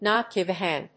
アクセントnót gìve [cáre] a háng